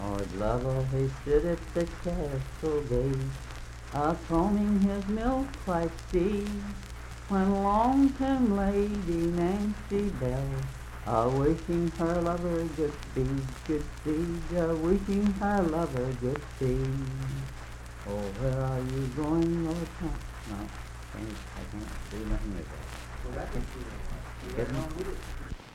Unaccompanied vocal music
Voice (sung)